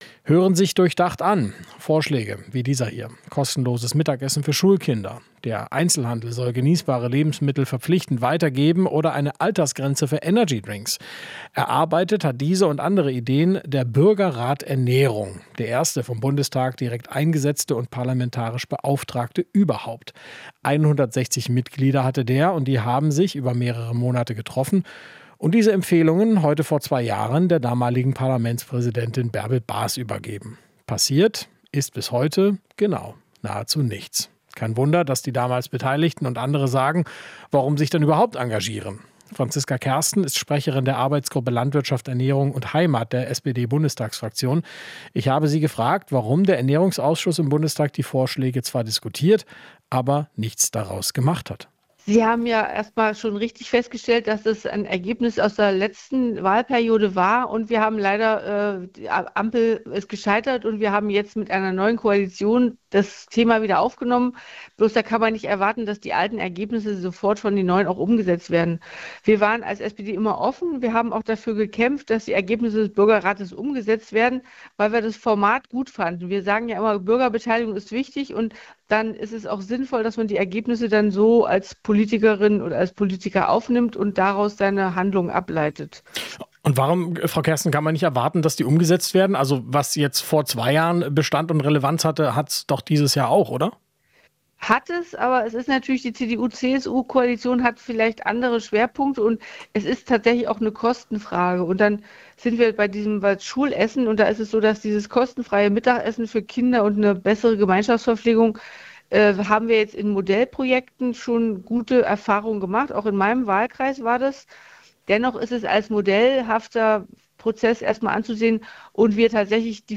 In Interviews, Beiträgen und Reportagen bilden wir ab, was in der Welt passiert, fragen nach den Hintergründen und suchen nach dem Warum.